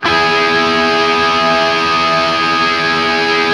TRIAD E  L-L.wav